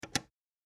ボタン・システム （87件）
スイッチ6.mp3